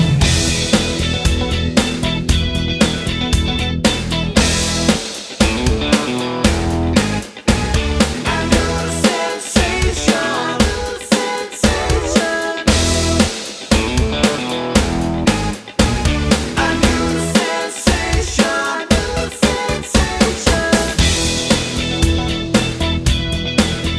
no sax Pop (1980s) 3:40 Buy £1.50